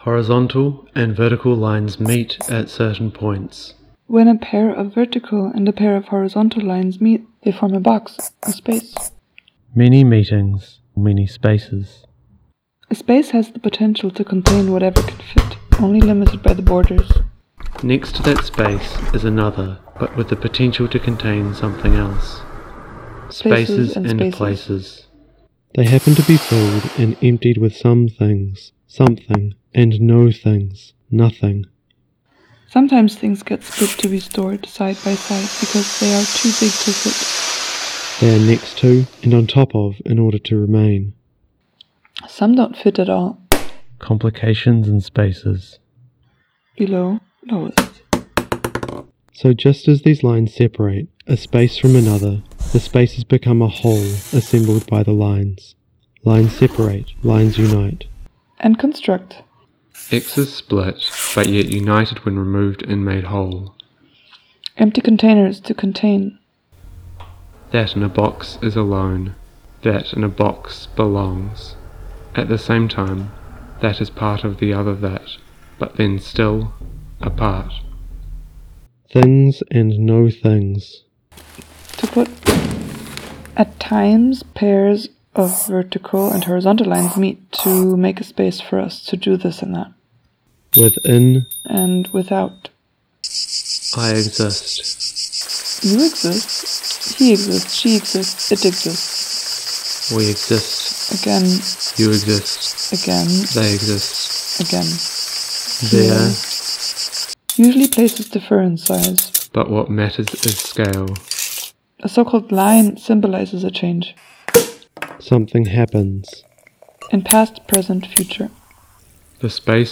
The book performed as a Hörspiel (2020).